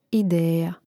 idéja ideja